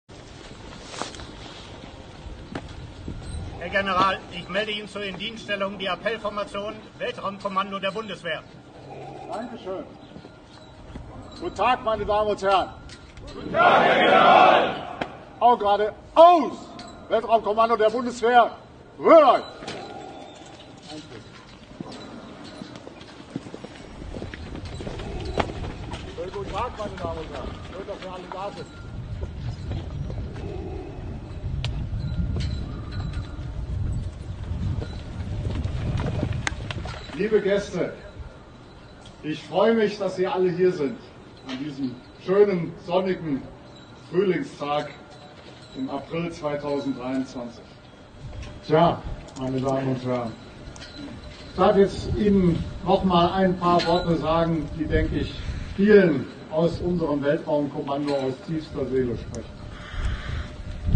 Der Gipfel des ganzen ist dann jetzt beim Festakt zum Bezug des neuen Gebäudes zu sehen. Da marschieren doch tatsächlich imperiale Sturmtruppen mit deren dunklen Anführern (inkl. Darth Vader Breath) ein.